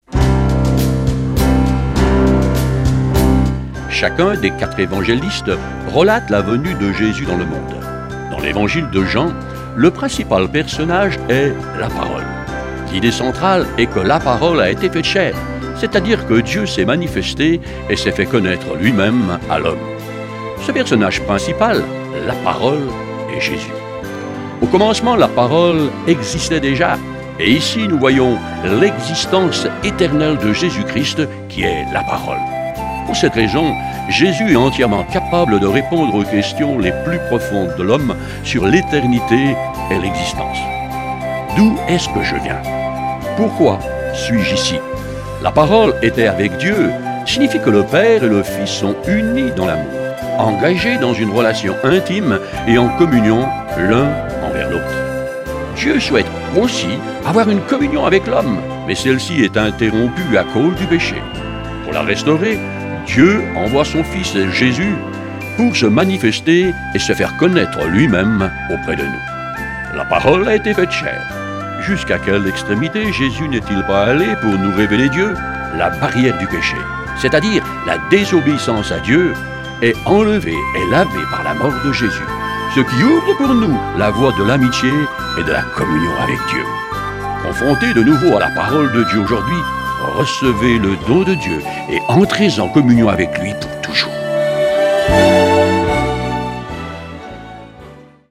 Une série de méditations pour le mois de Décembre